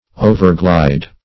Overglide \O`ver*glide"\